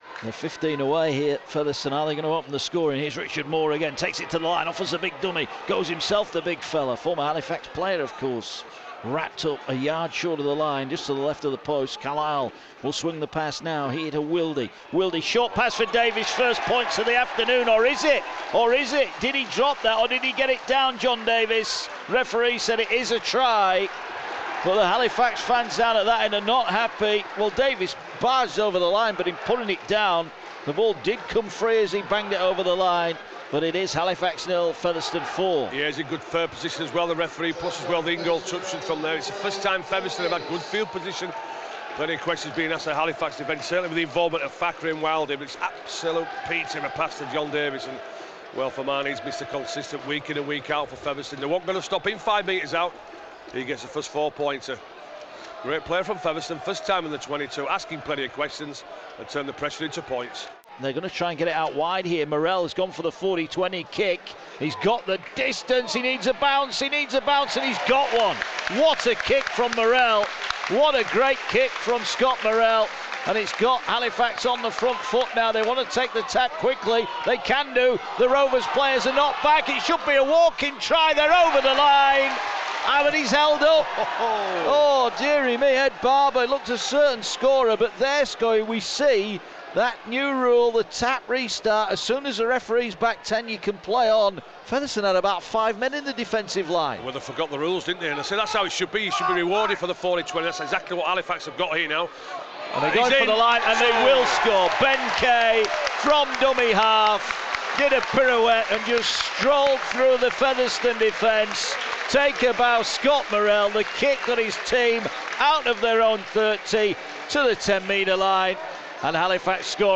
Fev edge out Fax 26-20 in an entertaining match that sees them get an extra £50k in funding for next season. Commentary